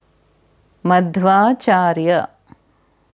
Madhvacharya मध्वाचार्य madhvācārya Aussprache
Hier kannst du hören, wie das Sanskritwort Madhvacharya, मध्वाचार्य, madhvācārya ausgesprochen wird: